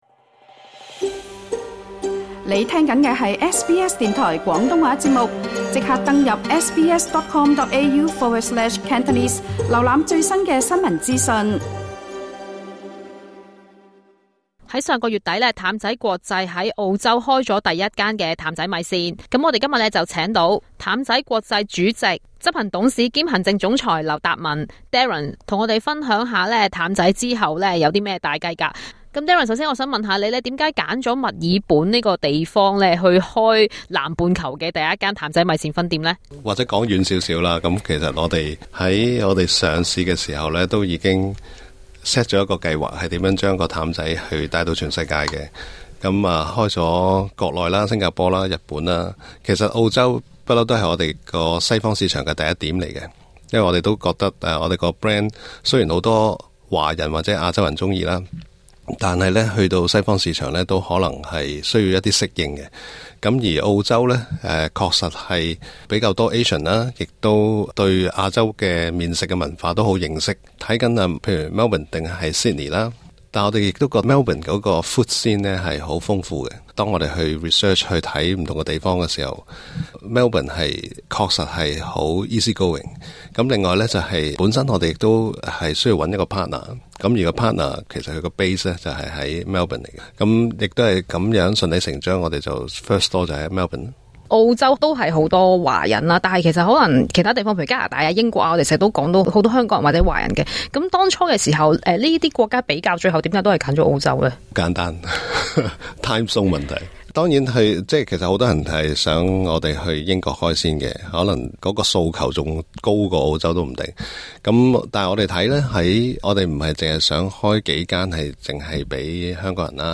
更多訪問內容，可收聽今集訪問。